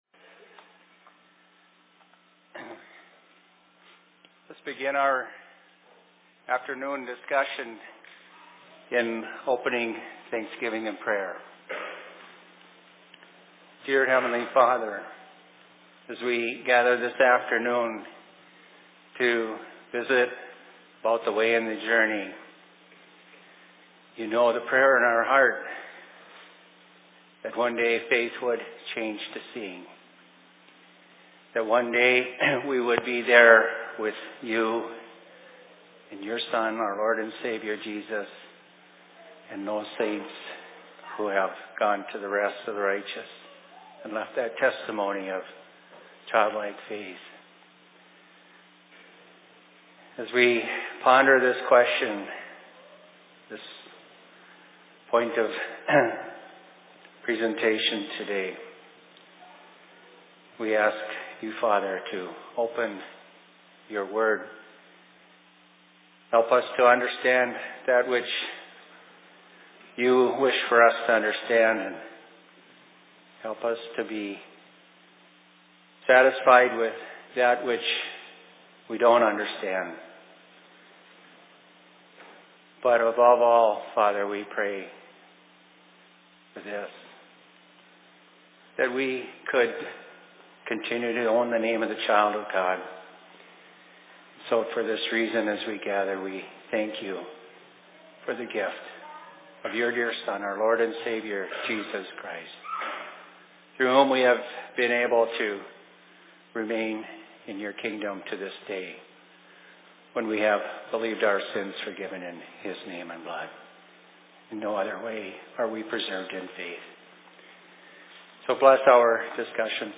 Presentation in Minneapolis 25.11.2018